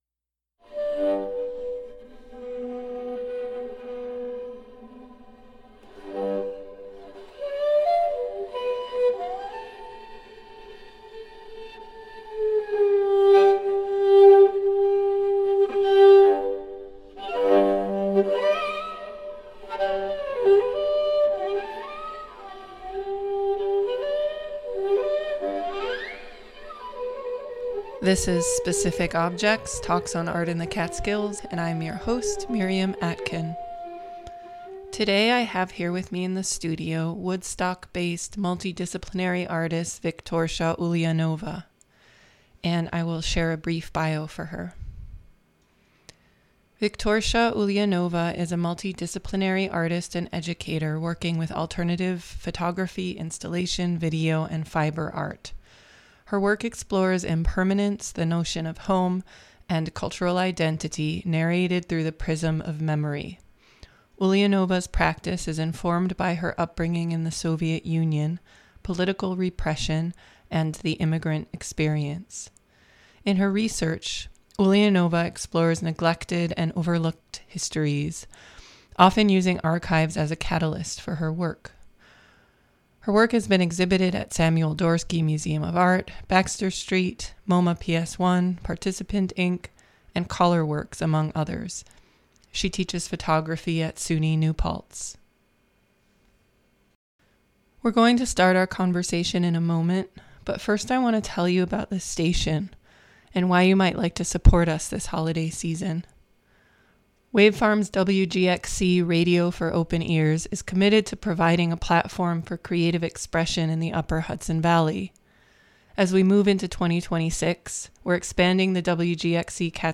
"Specific Objects" is a monthly freeform discussion
Intro music